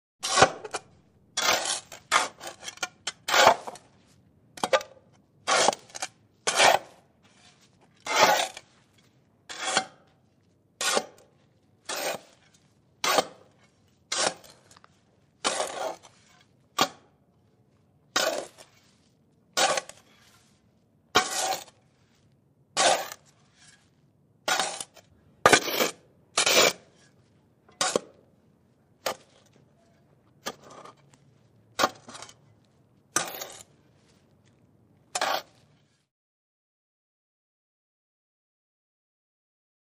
Shoveling | Sneak On The Lot
Shoveling Dirt; Light Layer Scrapes On Asphalt.